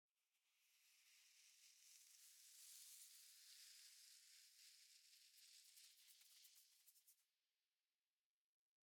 movingsand1.ogg